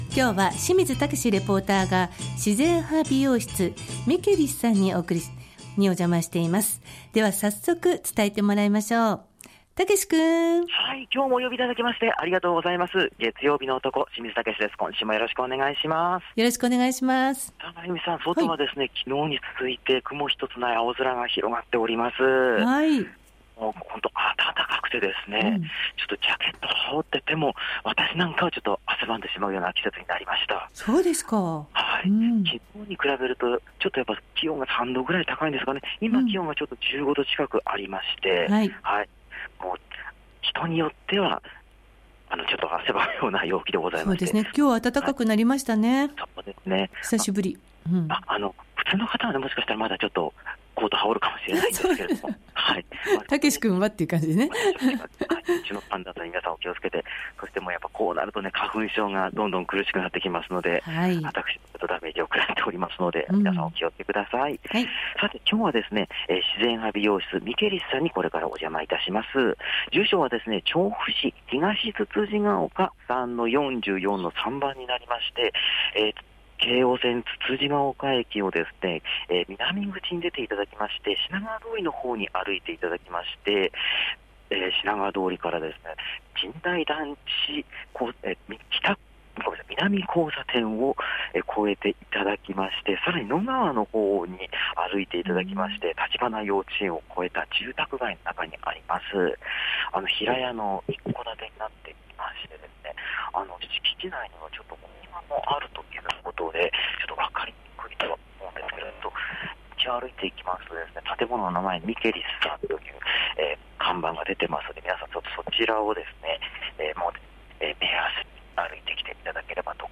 先週は雪が降りましたが、今週は雲一つない暖かい青空の下からお届けした街角レポートは、 京王線・つつじヶ丘駅南口から徒歩12分ほどの場所にある「自然派美容室ミケリス」さん からのレポートです！